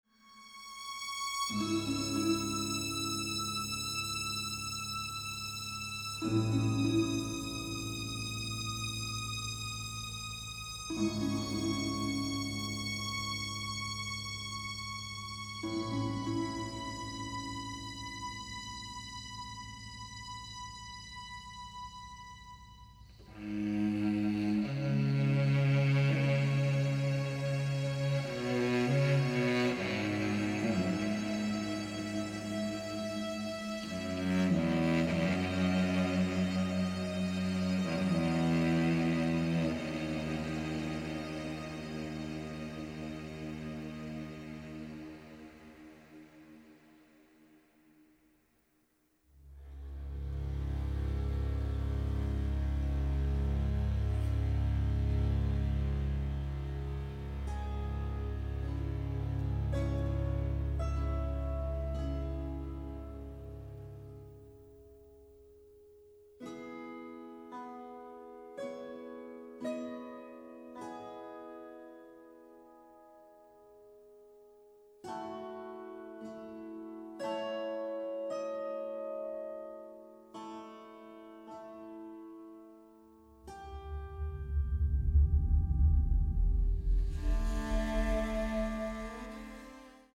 provocative and thoughtful orchestral score